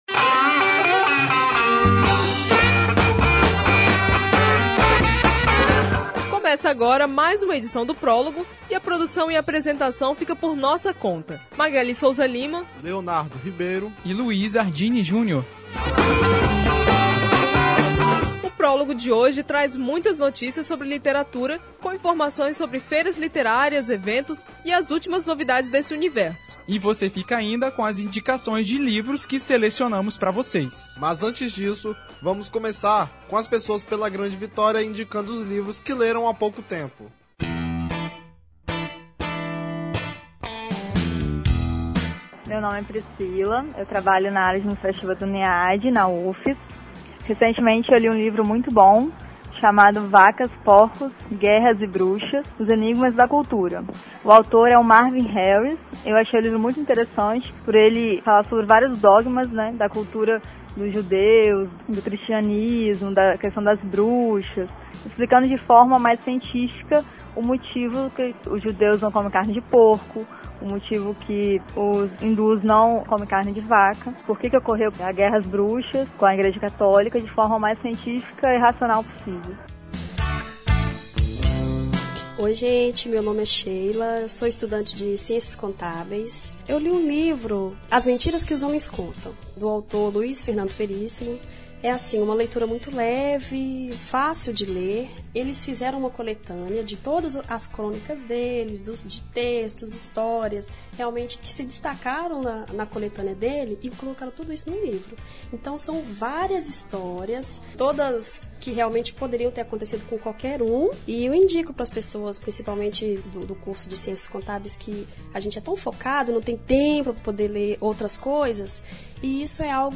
Pessoas da Grande Vitória também contribuíram contando sobre suas recentes leituras.